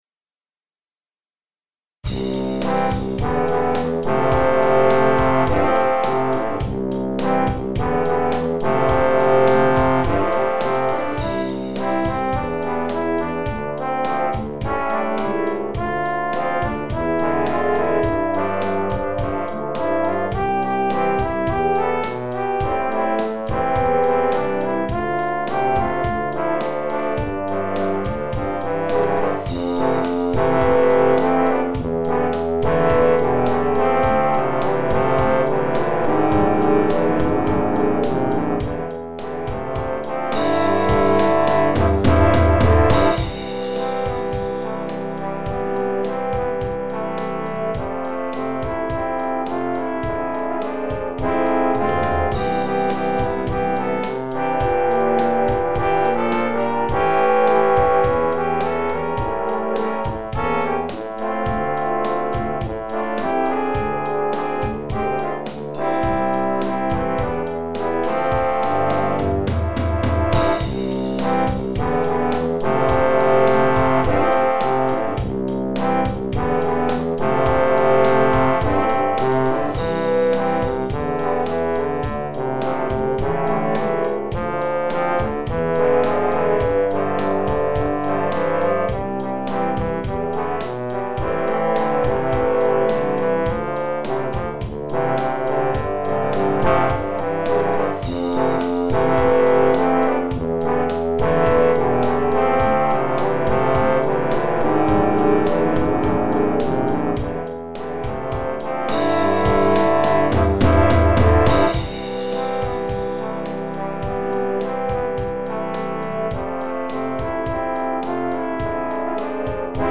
Scores for instrumental ensemble